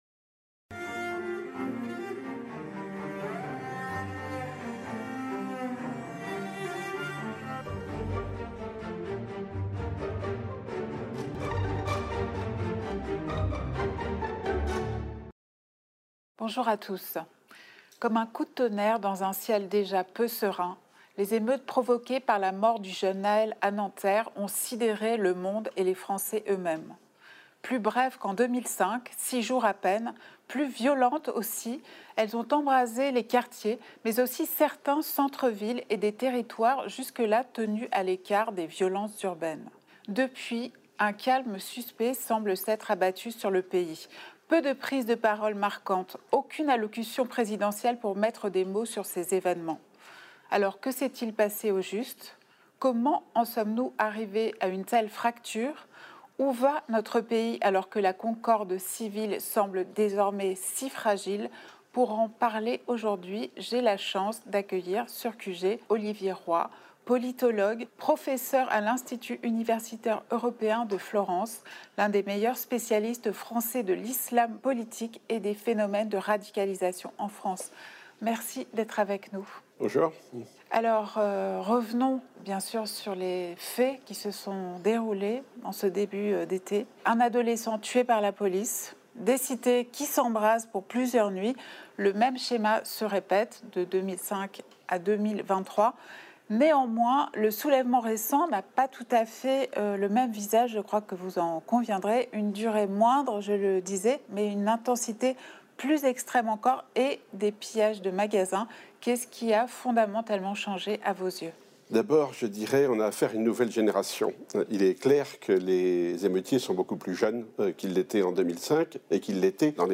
Aude Lancelin a reçu Olivier Roy, politologue, pour évoquer la révolte des quartiers et la crise du lien social. Des clivages générationnels dans l’immigration au besoin de politisation des banlieues, que racontent la violence des émeutes?